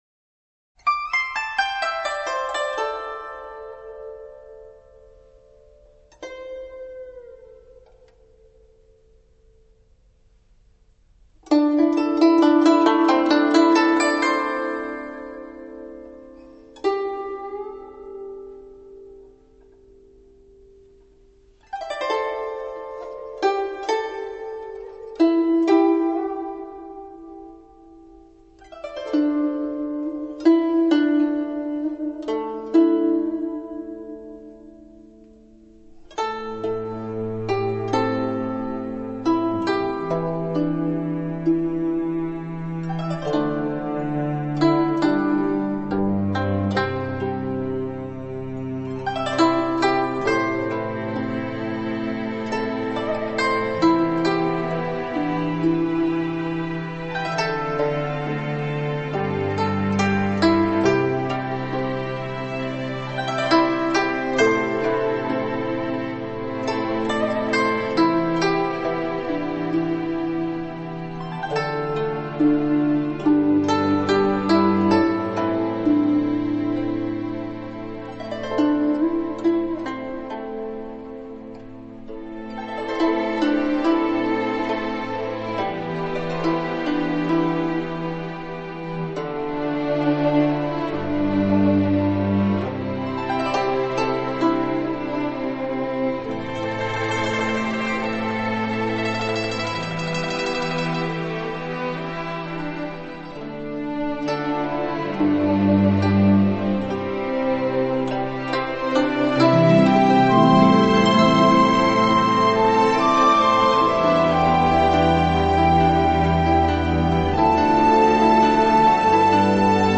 纯音乐
東洋の神秘と西洋のきらめきが融合したヒーリング?コンピレーション?アルバム第3弾
二胡
古箏
中国琵琶